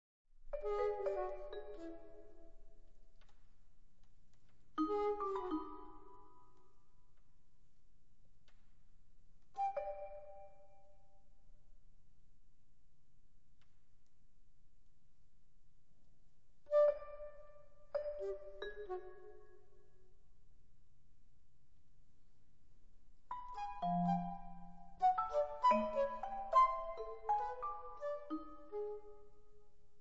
flauto barocco
percussioni
• registrazione sonora di musica